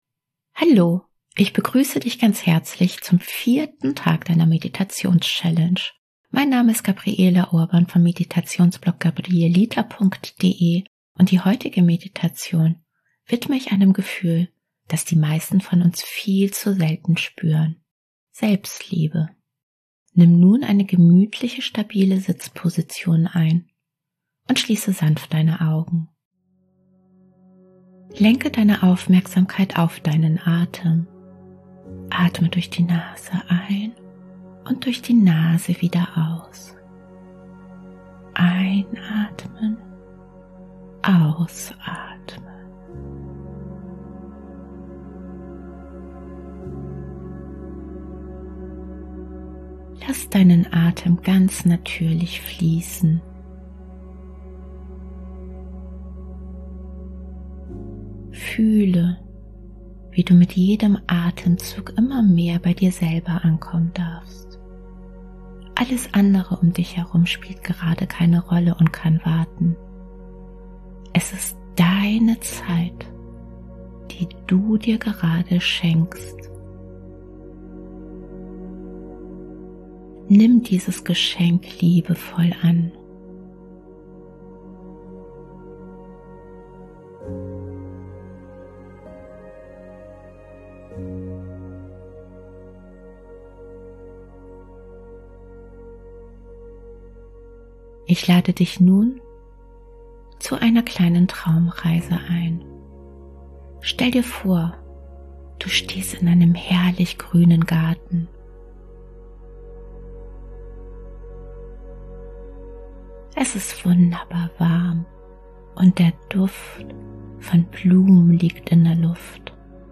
Meditations-Challenge
Traumreisen & geführte Meditationen